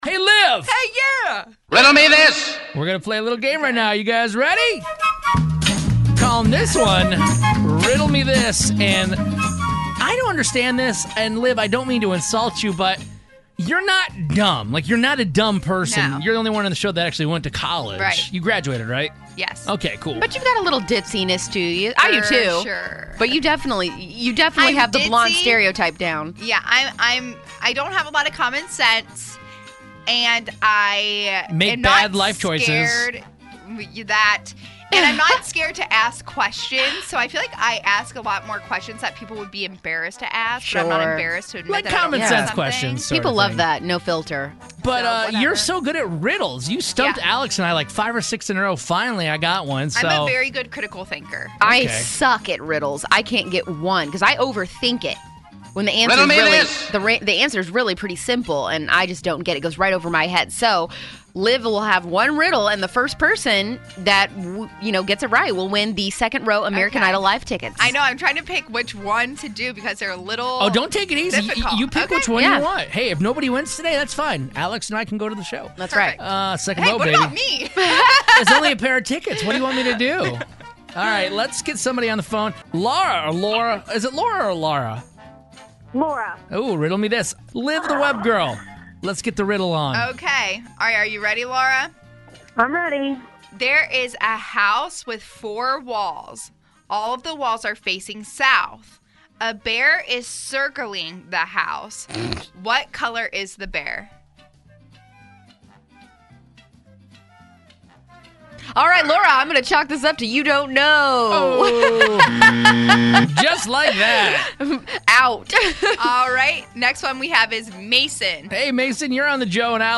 asks callers to answer a riddle for American Idol Tour tickets.